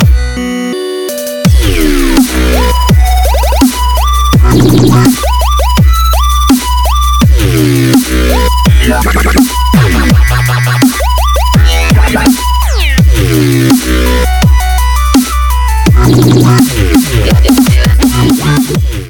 • Качество: 192, Stereo